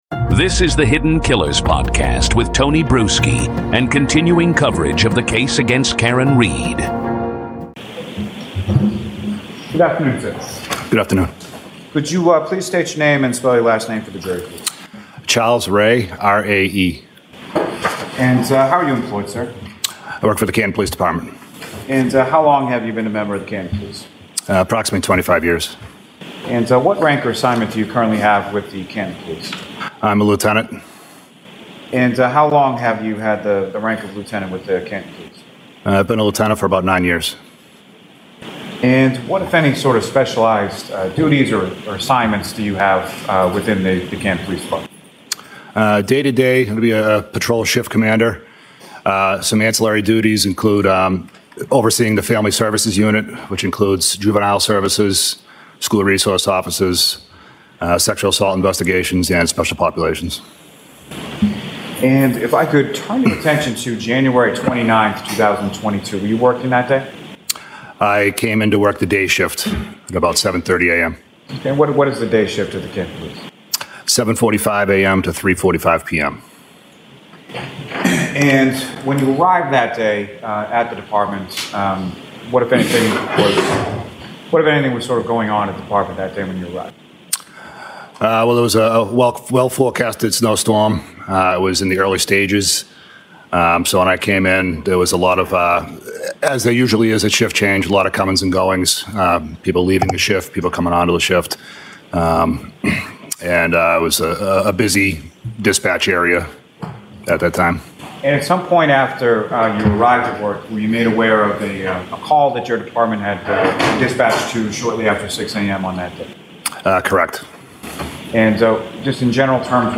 Karen Trial Raw Testimony